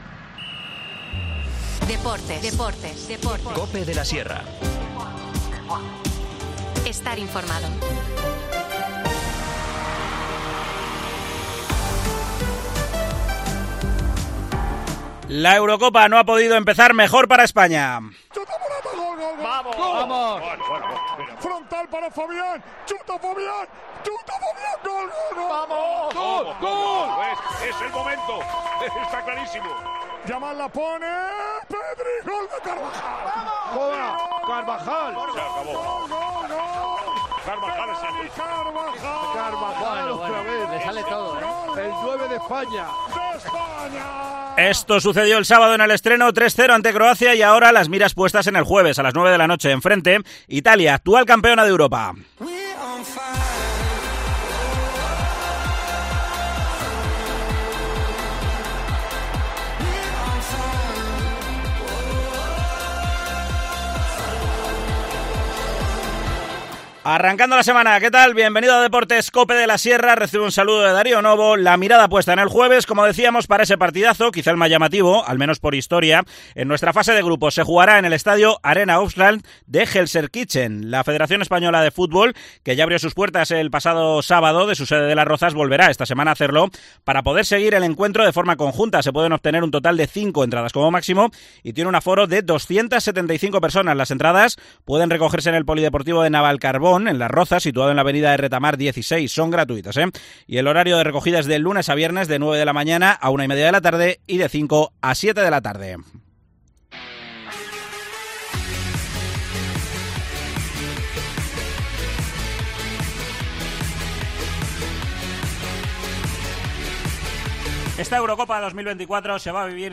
AUDIO: Salimos a la calle para preguntar a los villalbinos por su porra para una Eurocopa 2024 que ha empezado muy bien para España con victoria por...